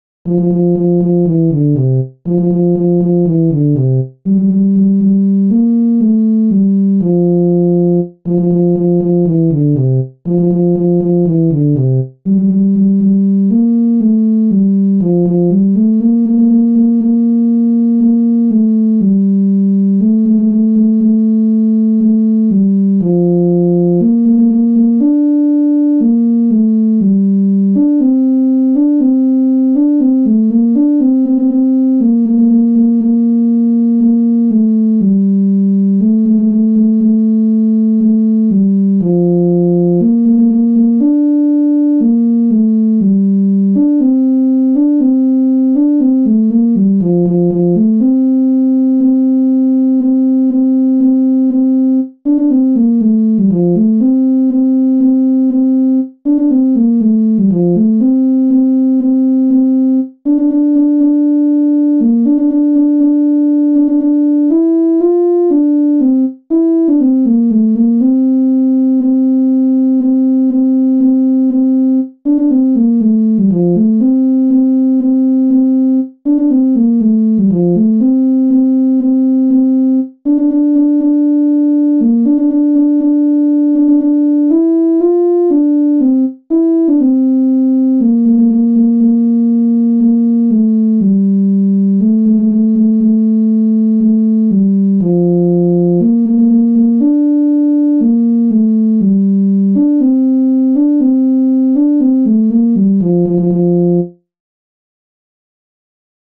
Jewish Folk Song (Chabad-Lubavitch melody)
F major ♩= 120 bpm